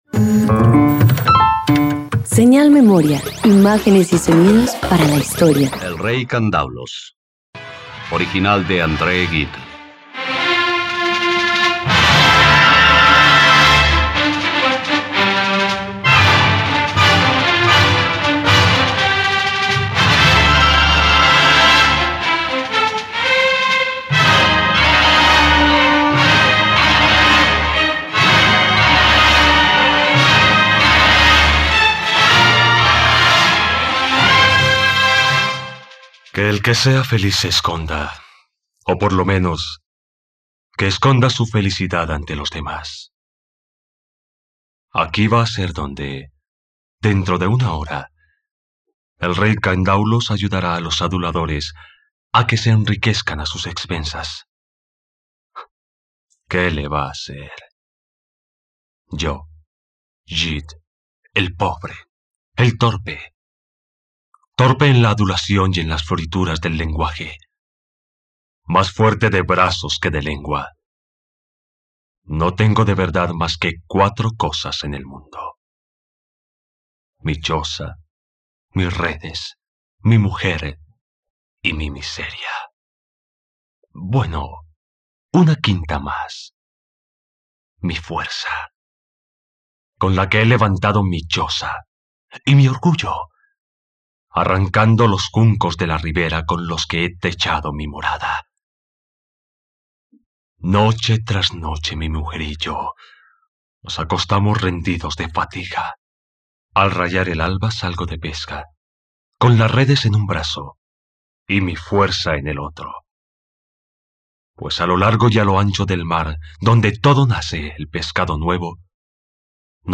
..Radioteatro. Escucha la adaptación radiofónica de “El rey candaules” de André Gide por la plataforma streaming RTVCPlay.